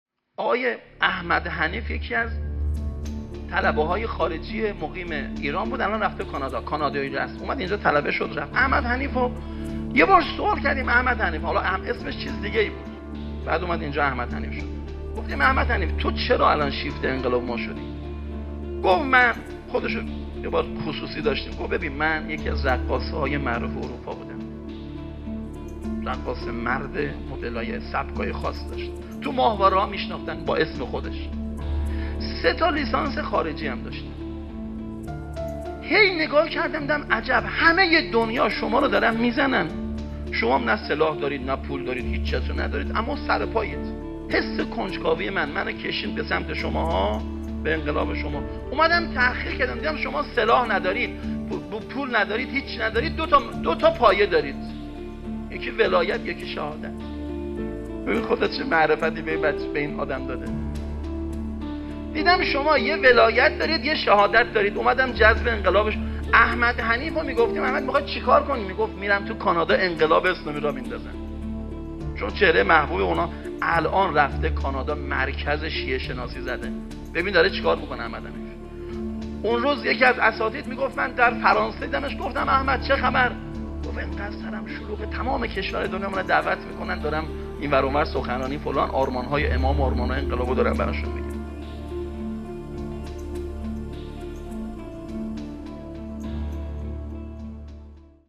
گزیده ای از سخنرانی
نخستین همایش هیأت های محوری و برگزیده کشور